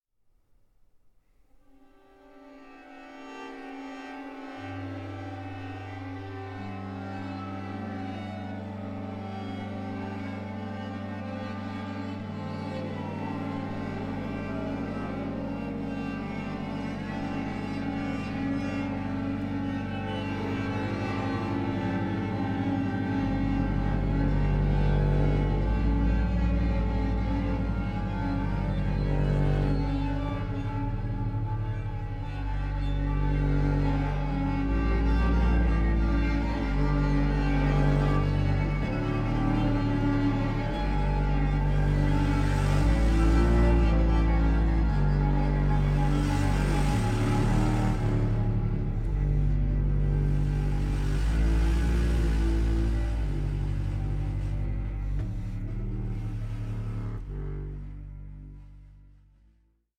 baroque orchestra